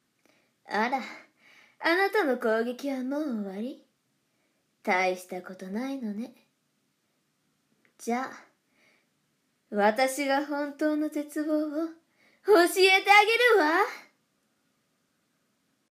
サンプルボイス 病み、恍惚 【少女】